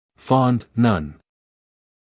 英音/ fɒnd / 美音/ fɑːnd /